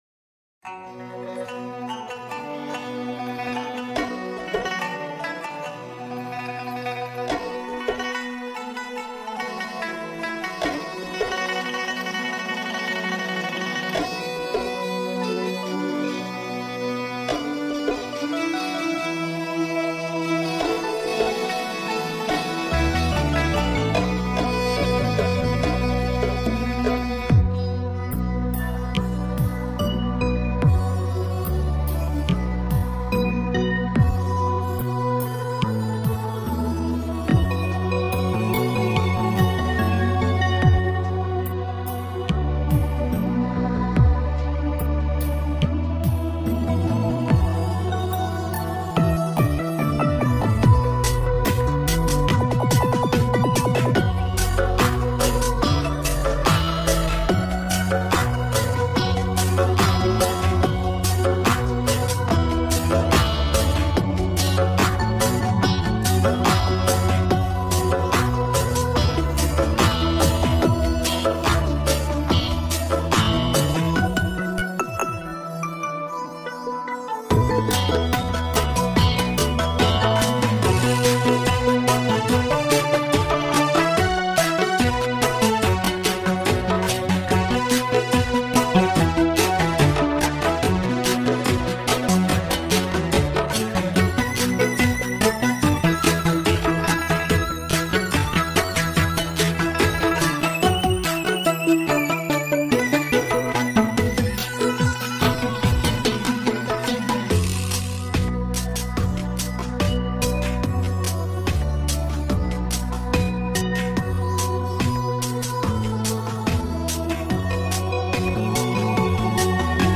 调式 : D